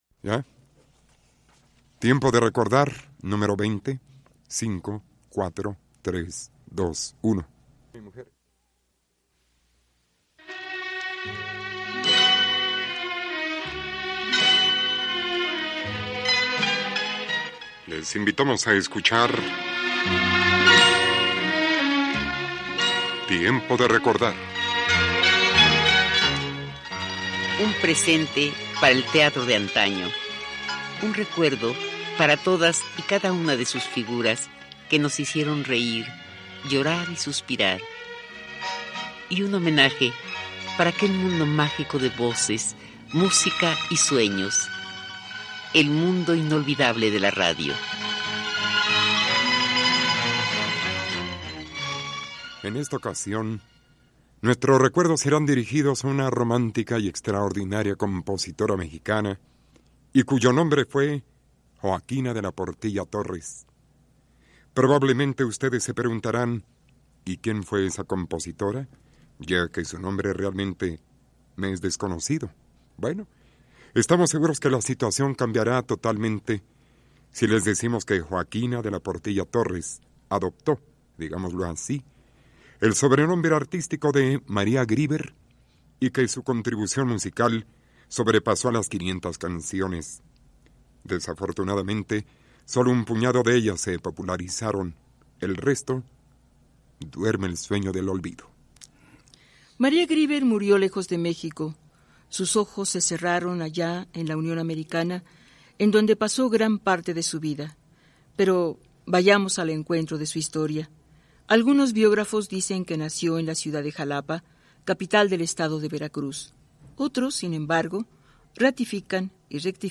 tenor mexicano.
cantante de ópera